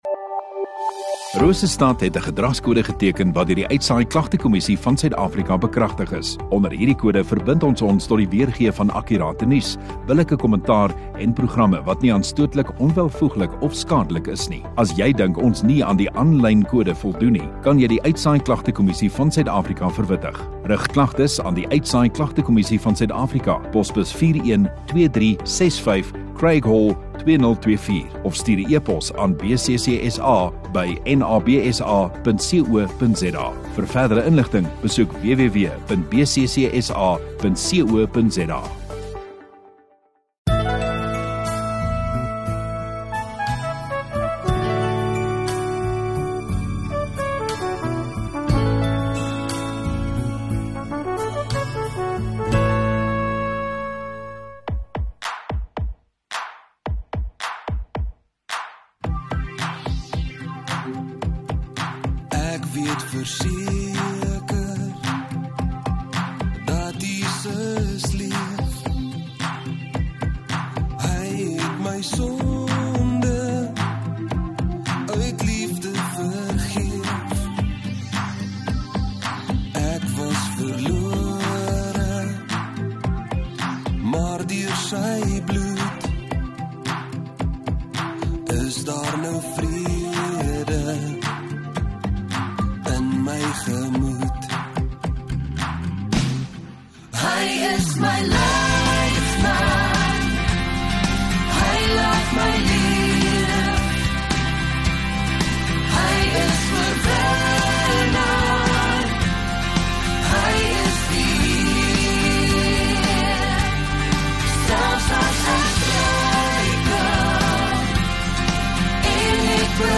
18 Jul Vrydag Oggenddiens